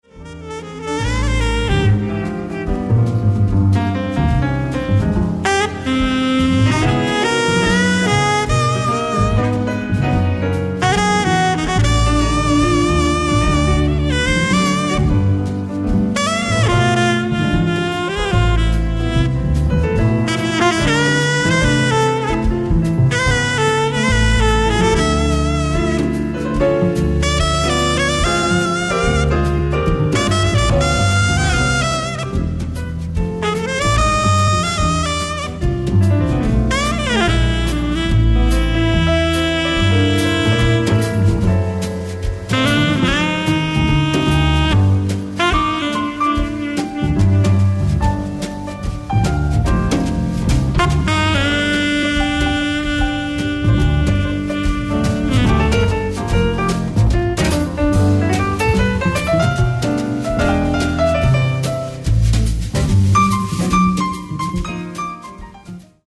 ライブ・アット・モントレー・ジャズフェスティバル、モントレー、カリフォルニア 09/22/2013
※試聴用に実際より音質を落としています。